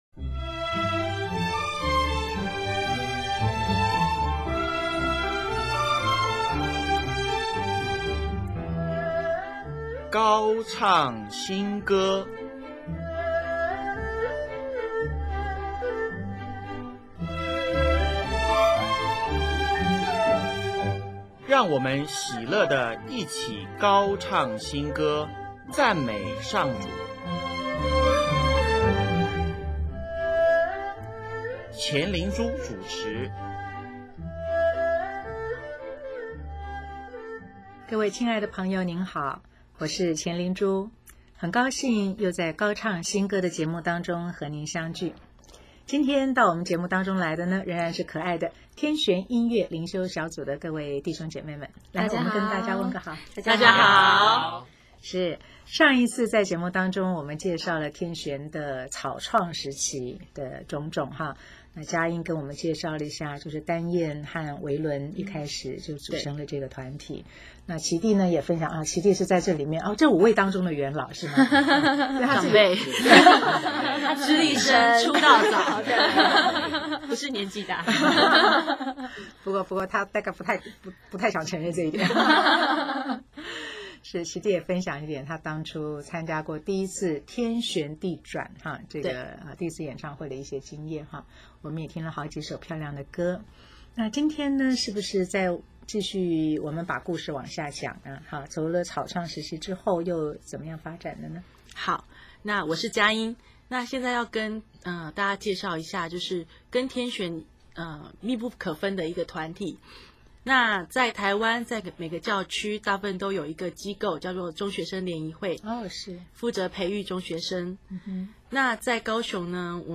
【高唱新歌】58|专访天旋音乐灵修小组(二)：一辈子的事